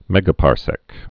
(mĕgə-pärsĕk)